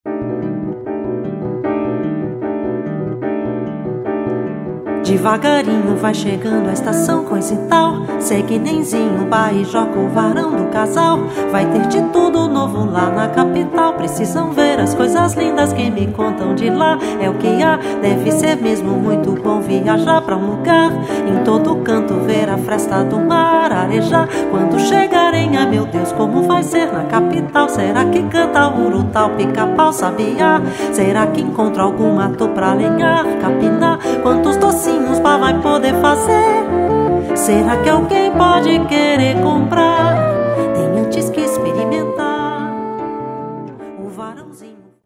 Voz
Clarinete
Percussão
Violoncelo
Guitarra e bandolim
Flauta e sax
Viola
Piano
Baixo, violão e programação eletrônica